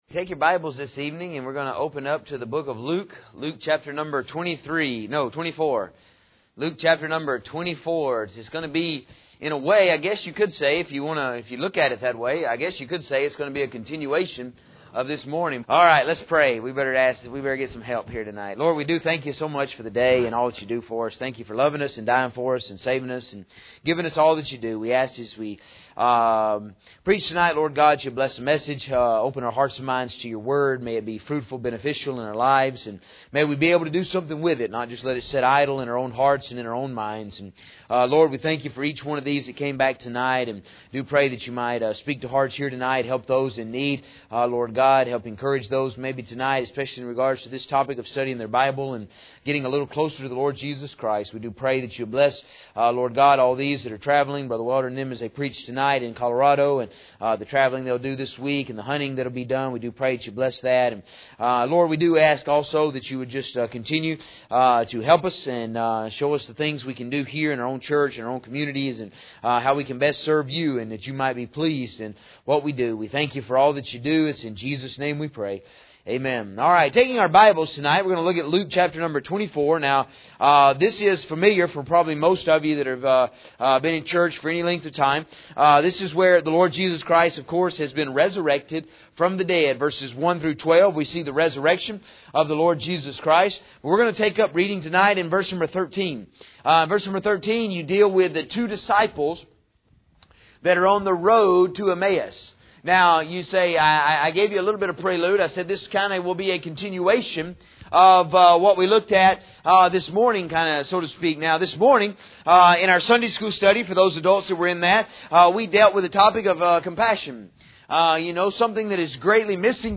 In this sermon we will look at the two disciples who were on the road to Emmaus and had Jesus appear unto them.